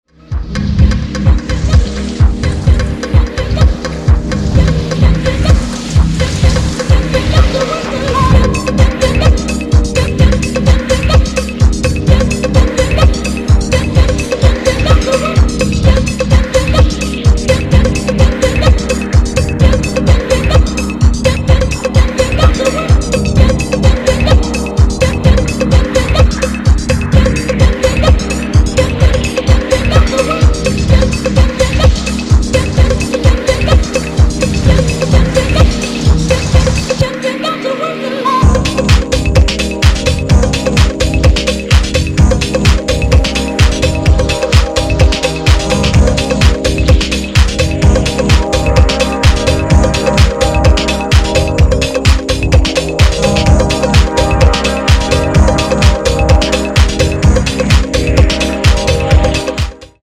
classic house sounding